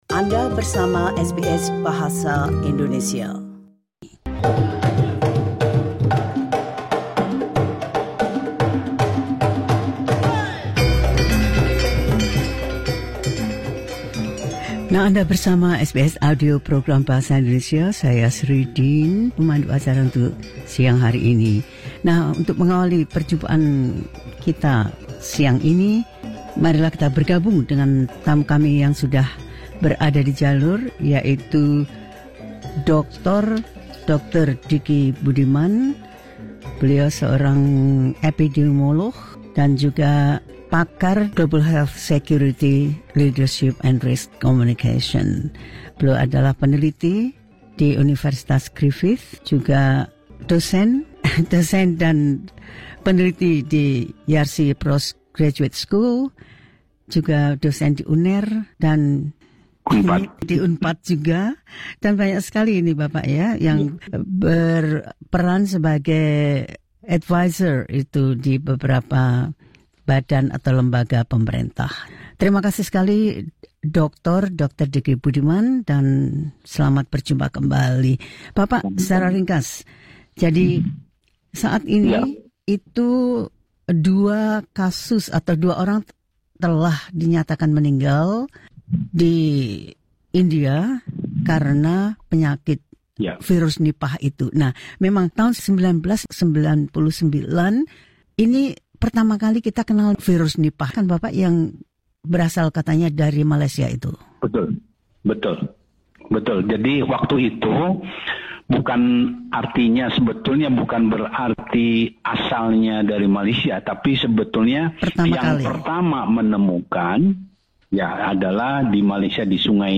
In an interview with SBS Indonesian